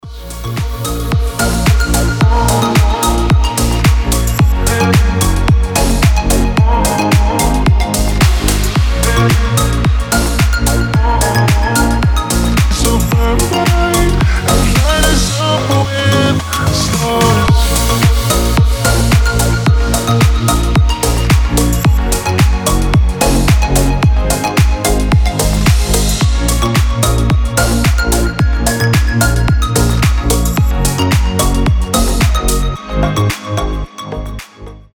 Дип-хаус с нотками космоса